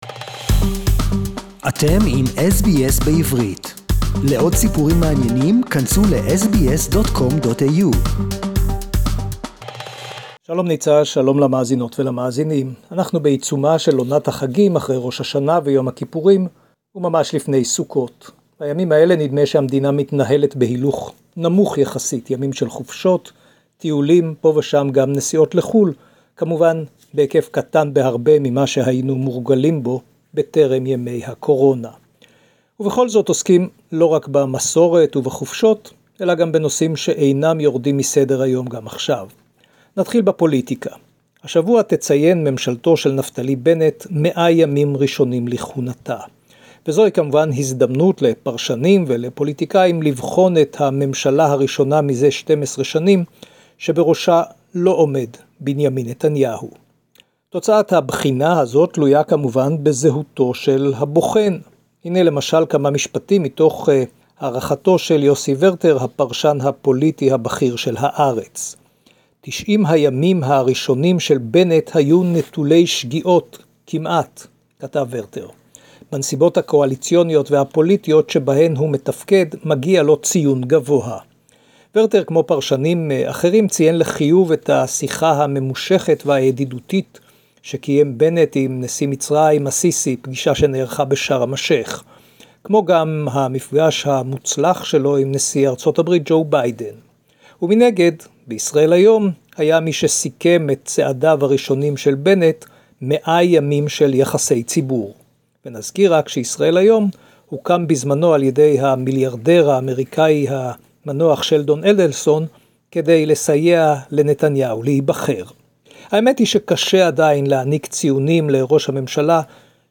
SBS Jerusalem report